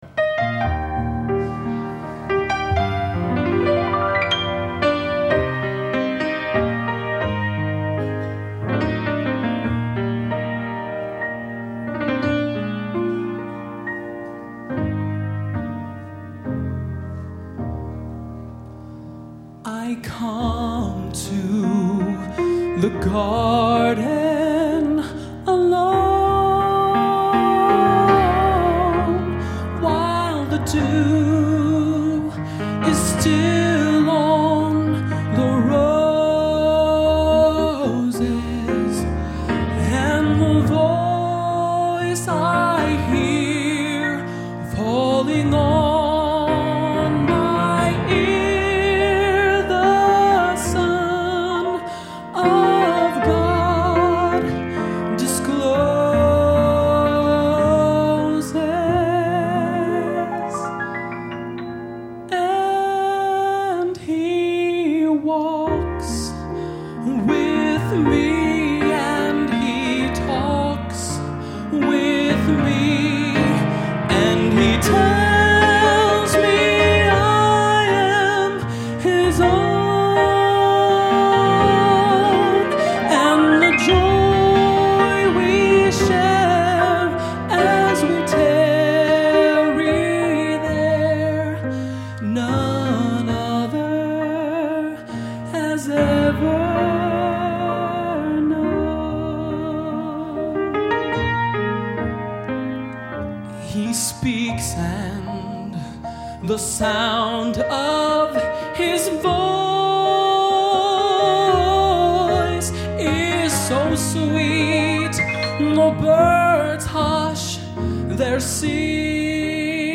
Location: Northrop High School, Fort Wayne, Indiana
Genre: Sacred Spiritual | Type: